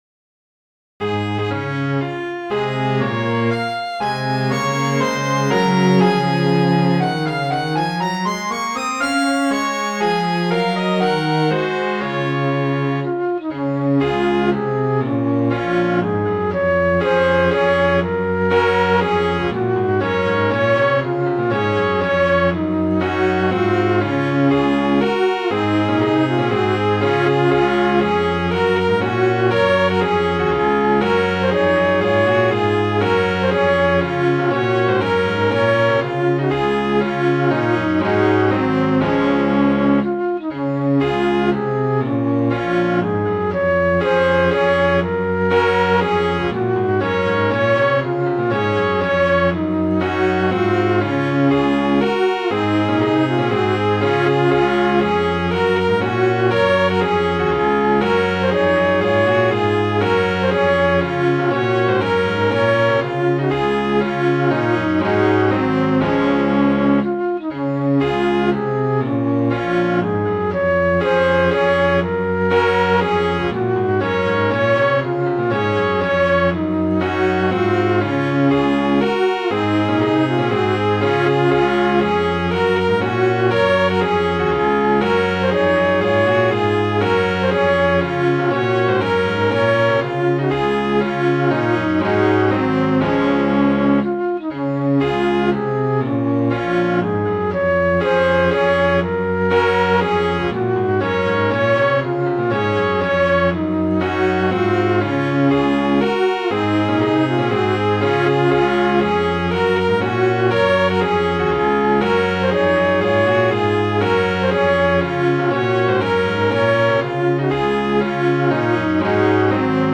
Midi File, Lyrics and Information to Britons, Strike Home
an old sea song which was very popular with schoolboys in his youth